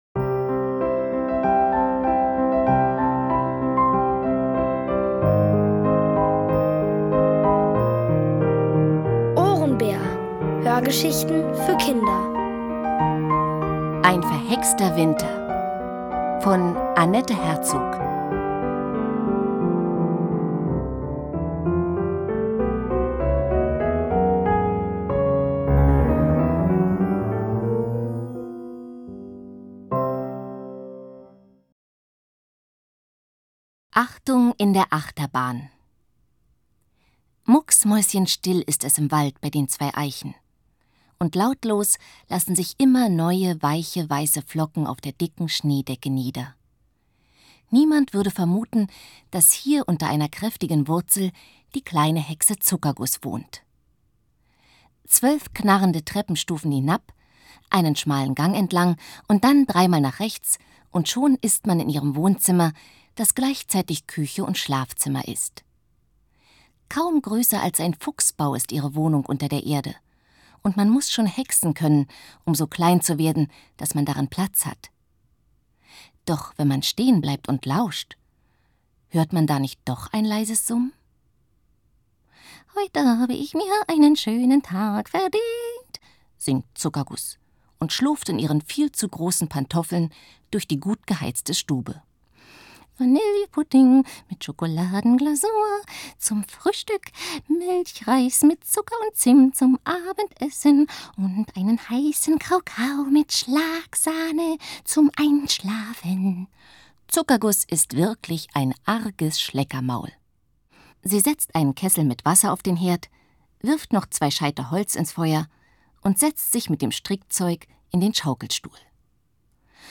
Von Autoren extra für die Reihe geschrieben und von bekannten Schauspielern gelesen.
Kinder & Familie